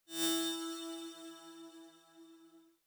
SFX_Menu_Confirmation_09.wav